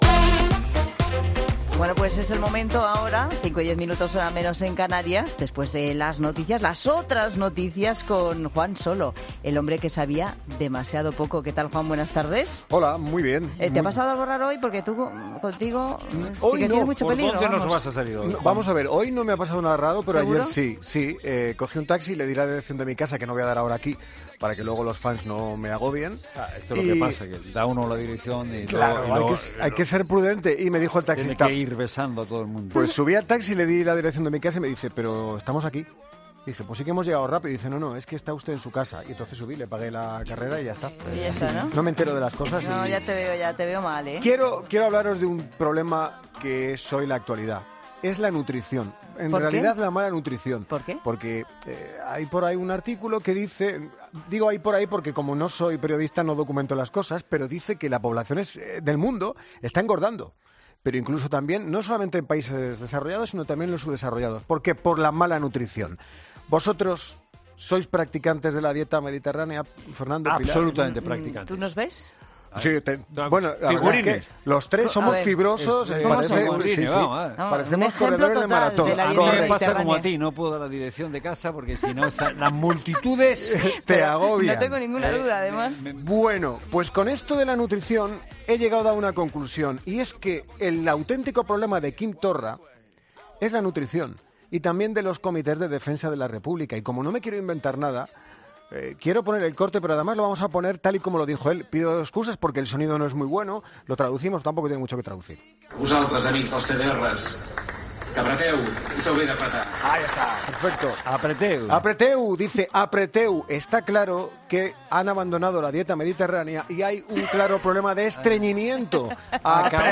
Humor: 'El hombre que sabía demasiado poco'